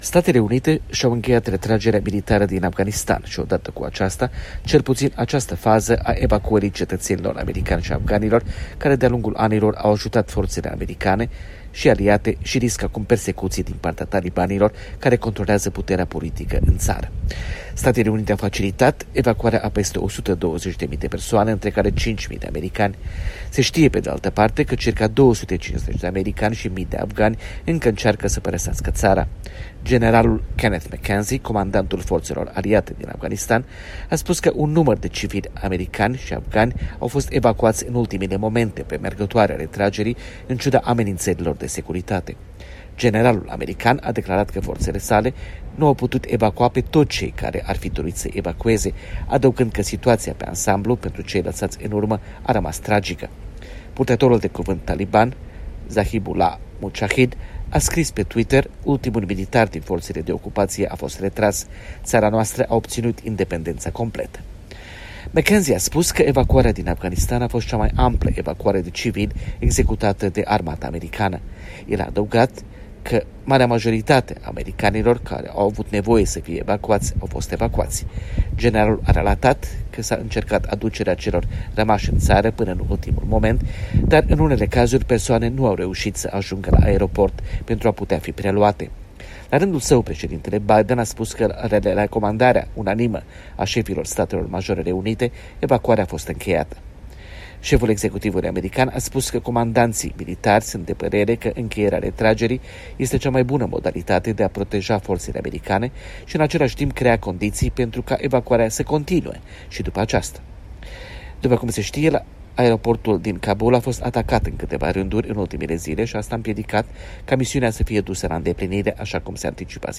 Corespondența de la Washington